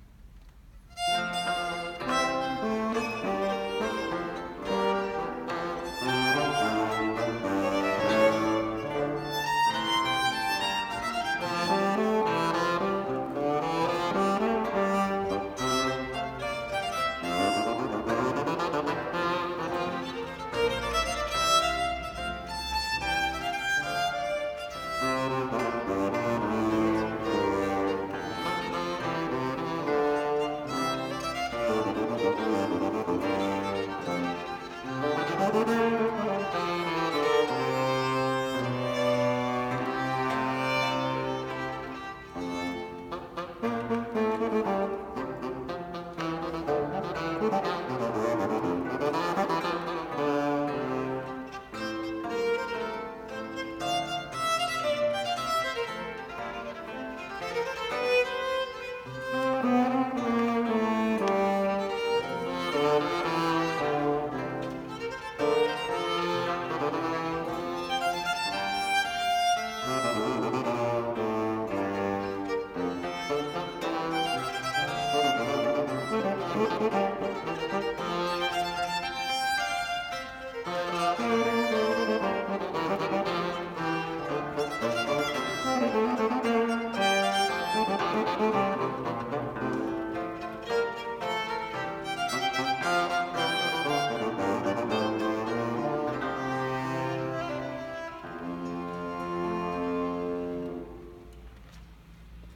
Sonata for Violin, Dulcian, and Continuo